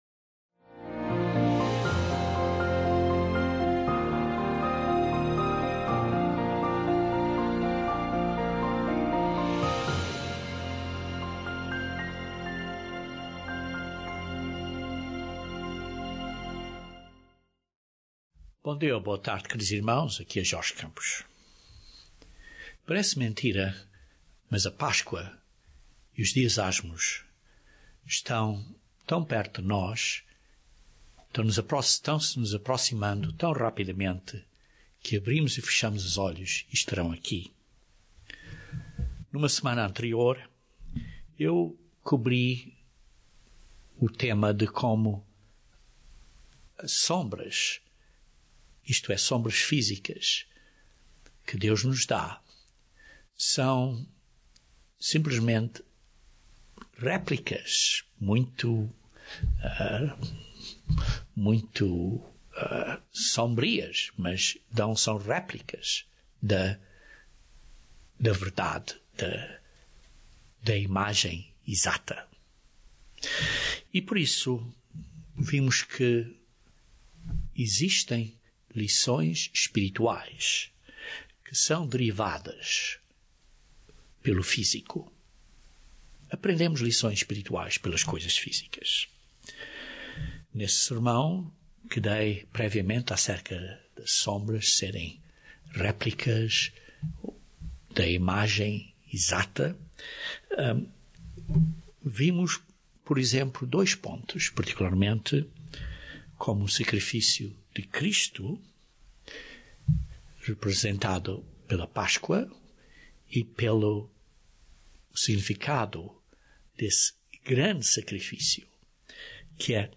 Consequentemente desejamos honrar Deus, fazendo o que é agradável a Deus, e imitando Jesus Cristo, desenvolvendo a mentalidade de Cristo e o caráter de Deus. Este sermão aborda este tema.